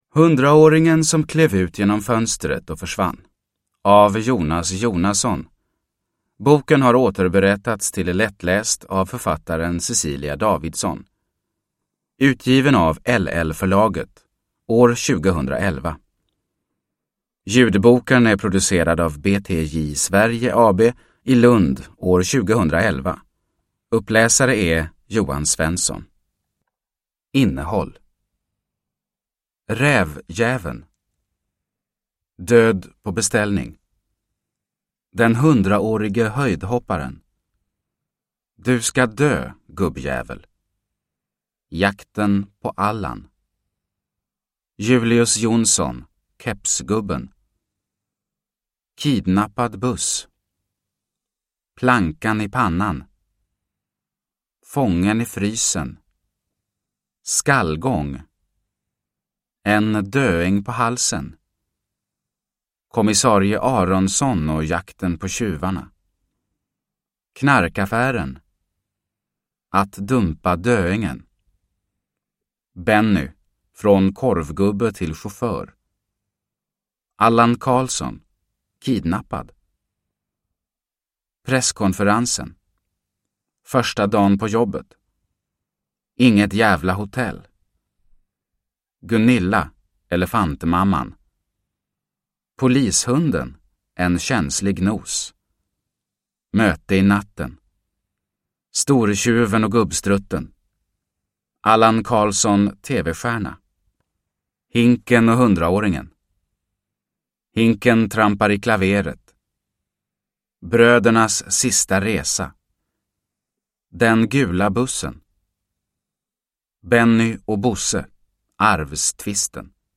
Hundraåringen som klev ut genom fönstret och försvann / lättläst / Ljudbok